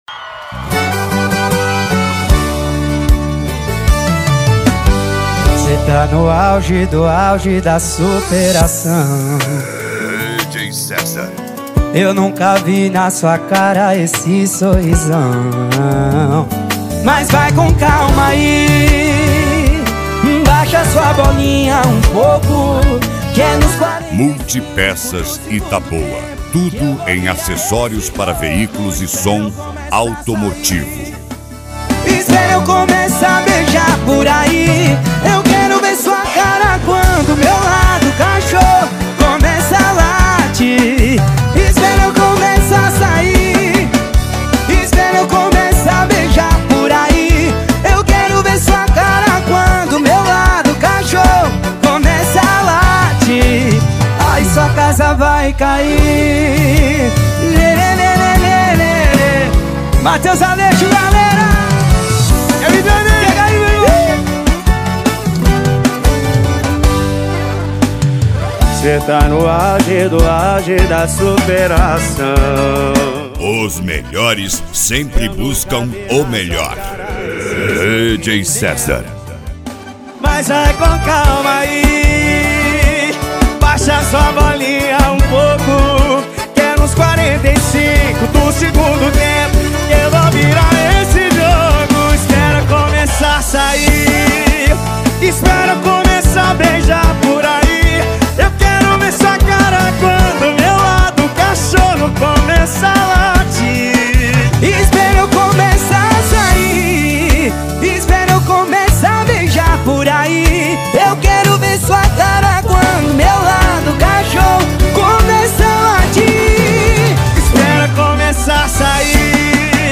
Moda de Viola
Modao
SERTANEJO
Sertanejo Raiz
Sertanejo Universitario